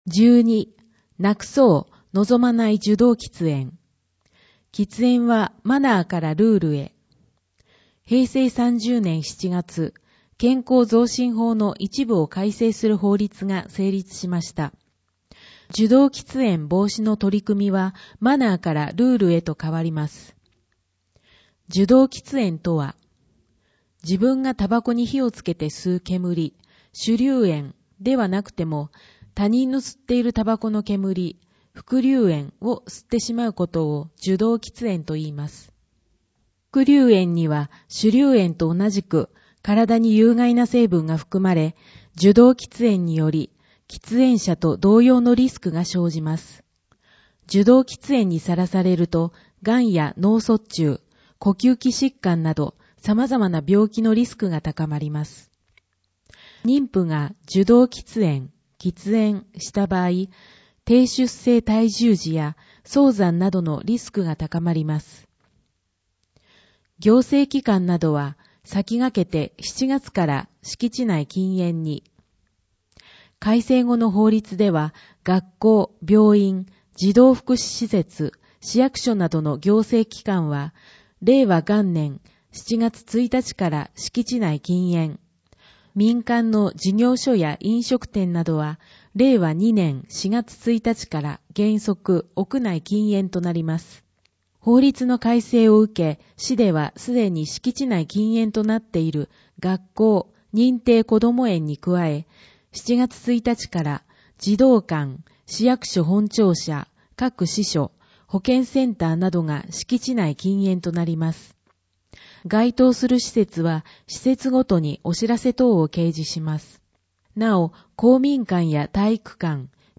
広報あづみの朗読版291号（令和元年5月22日発行)
「広報あづみの」を音声でご利用いただけます。